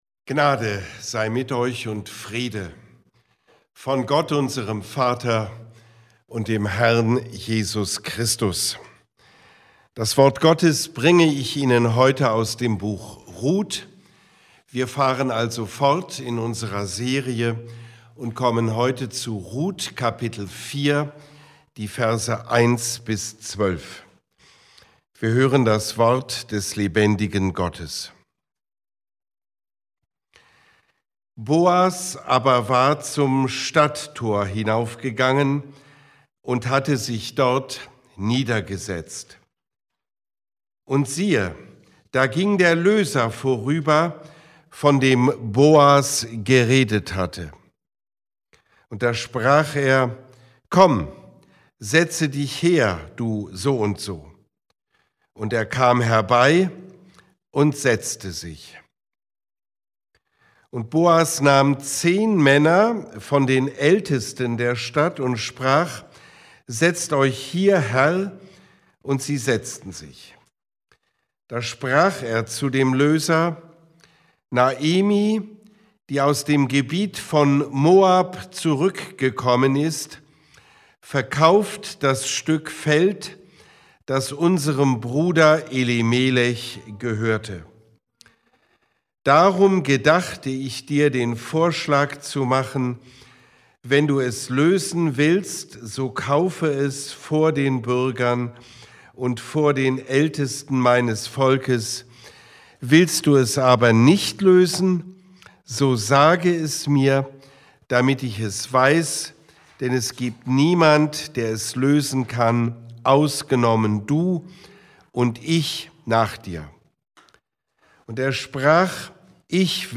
Diese Predigt lädt uns in das Stadttor von Bethlehem ein, wo Boas öffentlich Verantwortung übernimmt: Er wahrt Gottes Gebote, achtet bestehende Ordnungen und sucht Gottes Segen – nicht heimlich, sondern vor aller Augen.